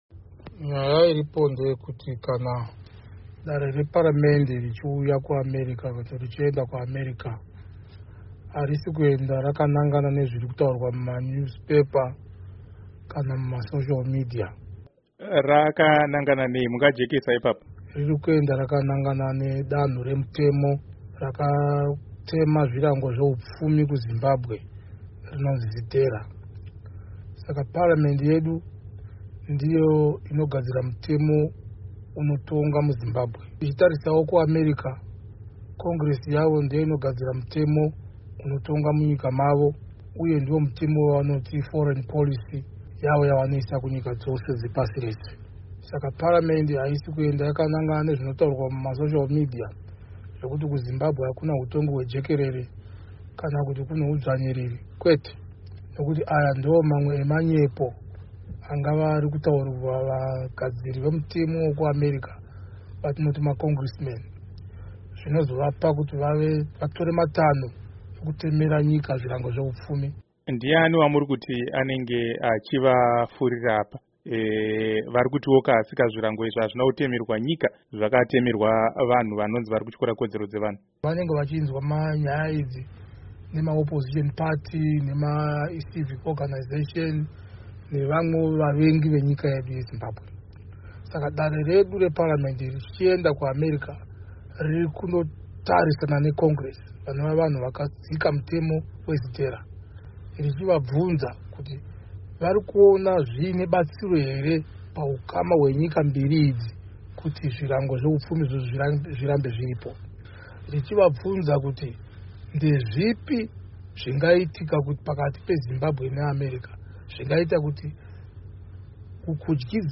Hurukuro naVaEnergy Mutodi